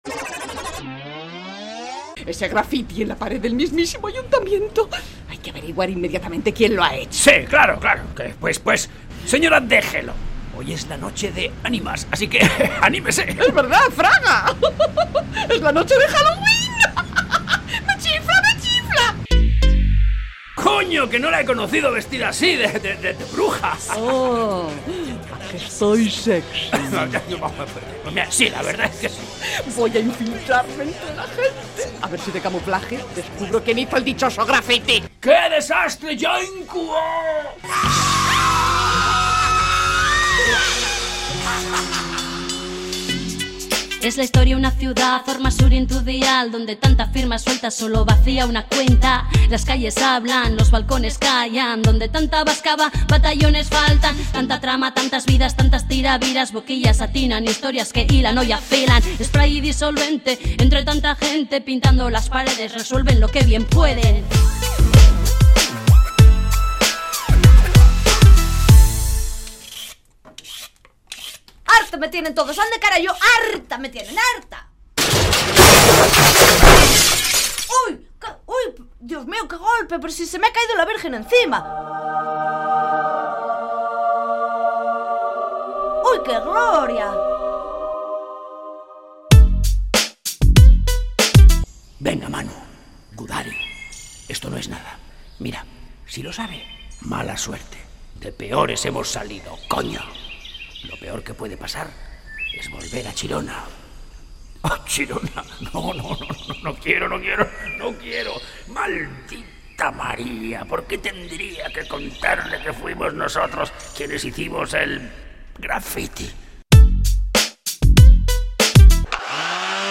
Entrega número 20 de la Radio-Ficción “Spray & Disolvente”